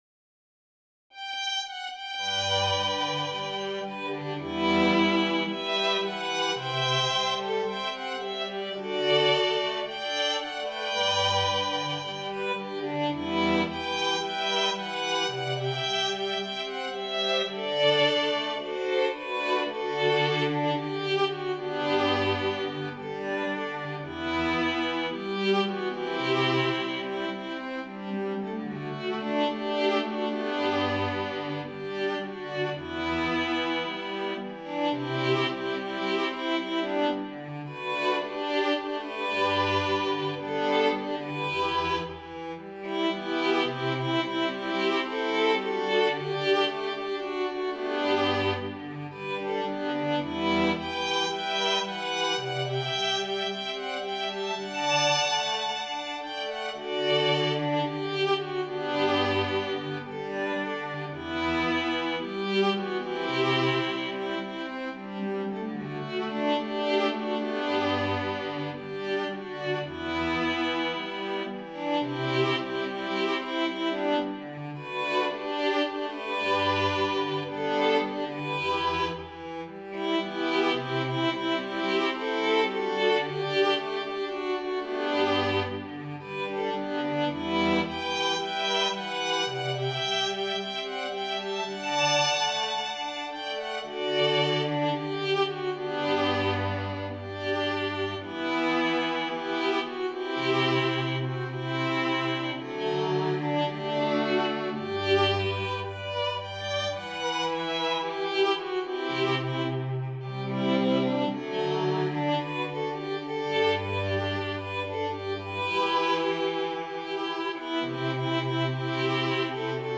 para Quarteto de Cordas
● Violino I
● Violino II
● Viola
● Violoncelo